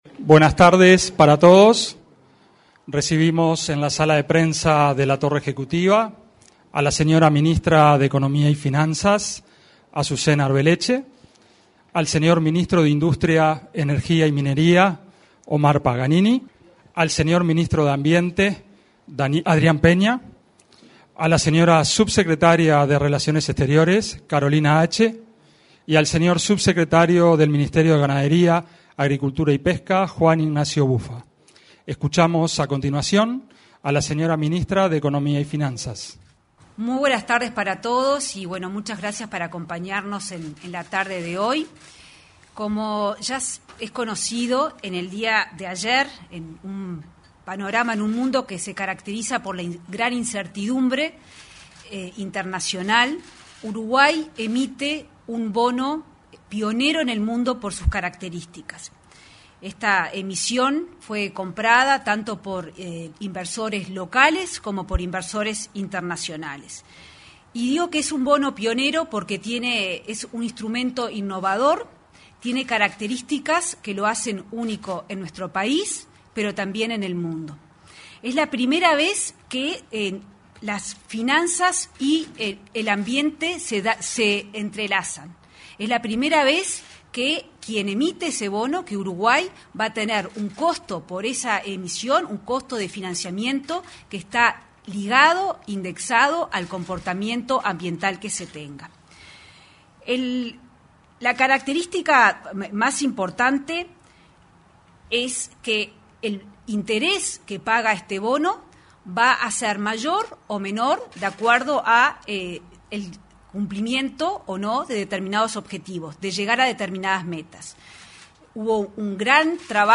La titular de Economía y Finanzas, Azucena Arbeleche, se expresó en conferencia de prensa sobre los bonos indexados a indicadores de cambio climático